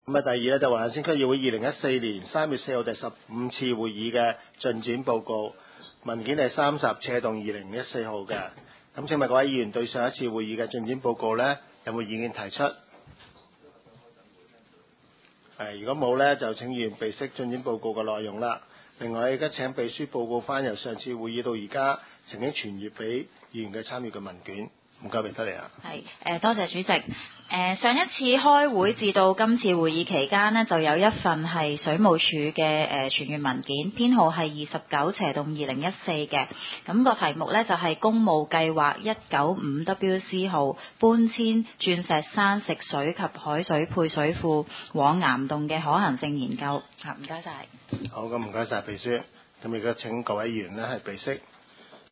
区议会大会的录音记录
黄大仙区议会会议室